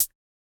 Index of /musicradar/retro-drum-machine-samples/Drums Hits/Tape Path A
RDM_TapeA_MT40-ClHat.wav